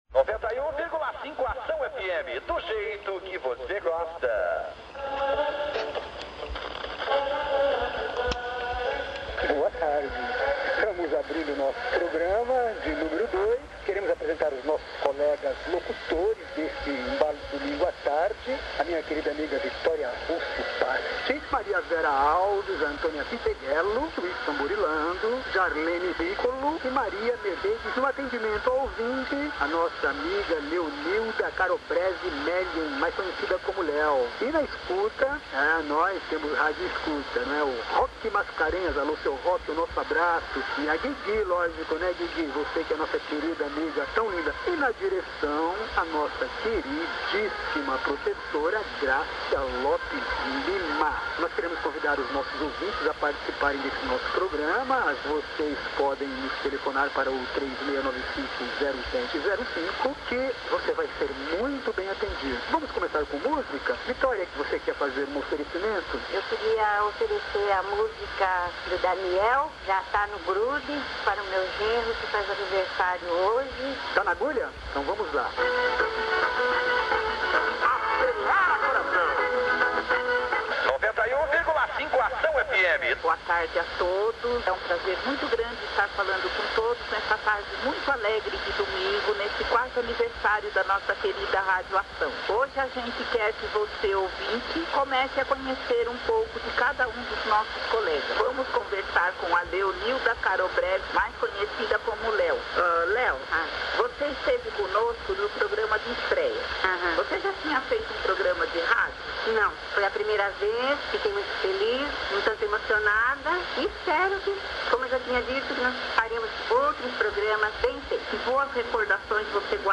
No estúdio com o cantor Ângelo Máximo
Coloco no aparelho de som um pequeno trecho do referido programa que apresentaram ao vivo – um tanto diferente do roteiro previamente planejado por eles em aula.